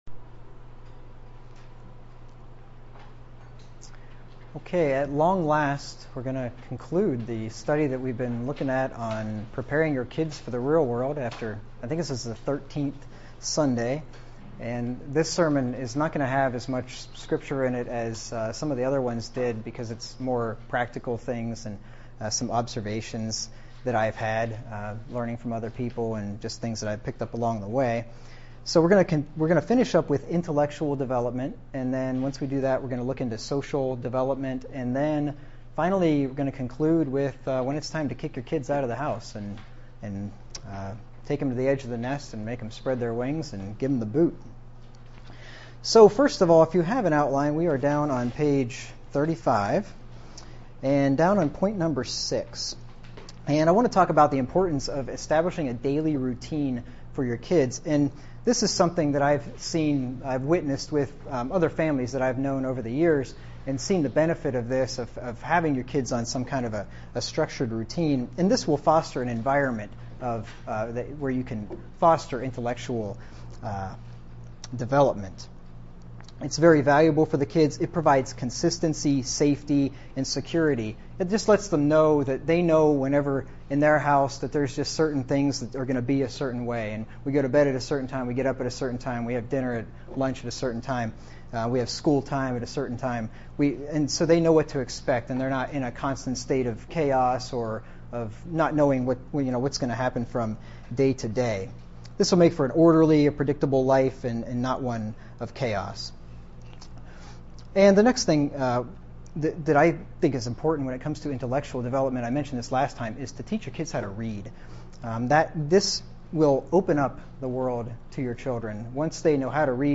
Watch the video of this sermon on YouTube: Preparing Your Kids for the Real World (Part 13) - Intellectual Development (Part C); Social Development; Kicking Them Out For the outline and the rest of the sermons in this series, click here: Preparing Your Kids For The Real World...